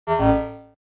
error.ogg